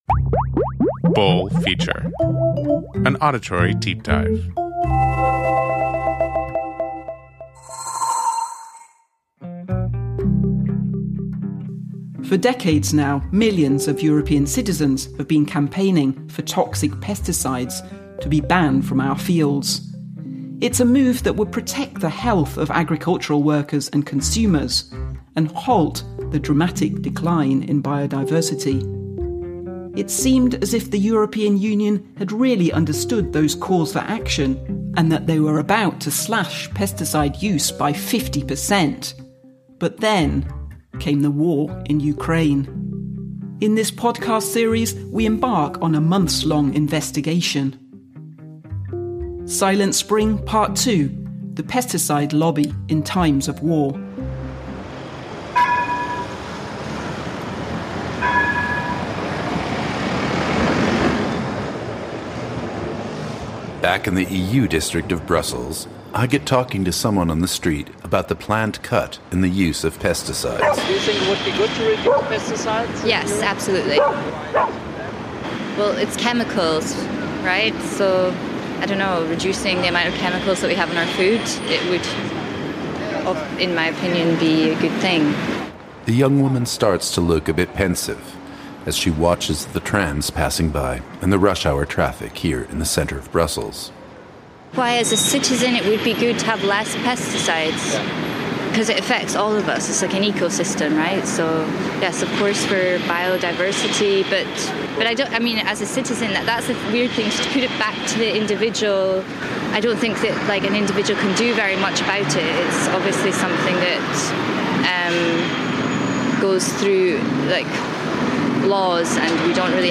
The conducted interviews and original sound recordings come from Europe (Berlin, Brussels and the region of Brandenburg).